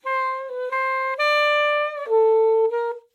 同行评估 7 " 萨克斯短句 - 声音 - 淘声网 - 免费音效素材资源|视频游戏配乐下载
来自aspma存储库的sax短语